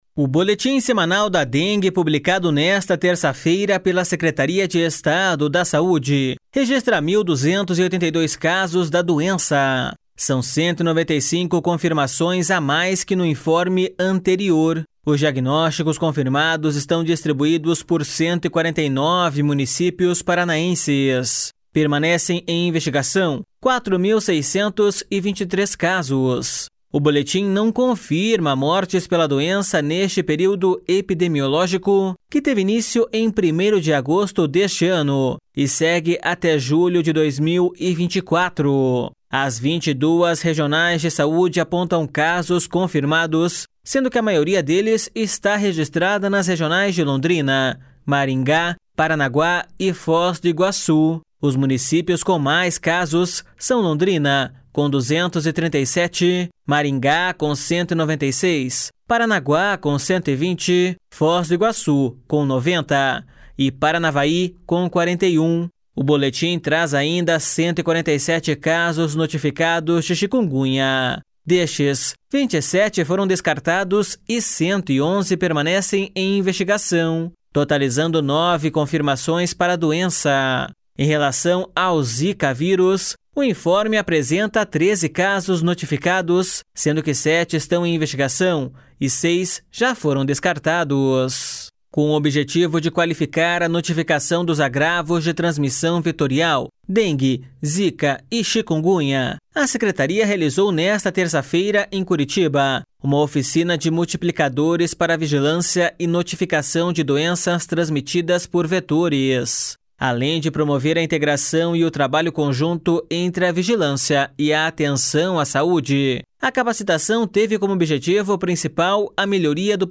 INFORME DA DENGUE DA SECRETARIA DA SAUDE.mp3